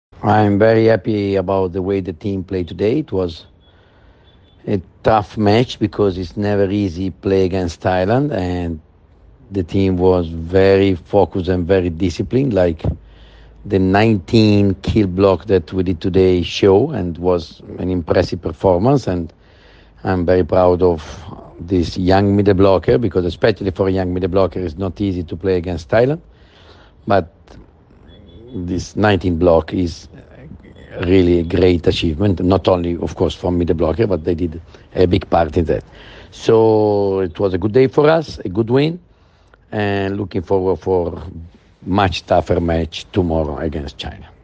Izjava Đovanija Gvidetija